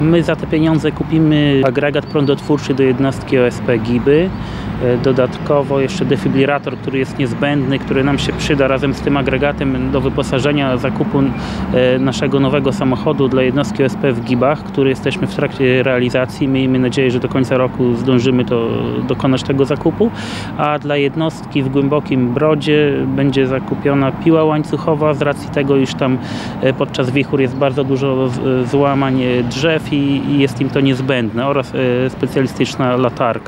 – Przykładowo, chociażby w gminie Giby, będzie to defibrylator, agregat prądotwórczy i piła mechaniczna – wymienia Robert Bagiński, zastępca wójta gminy.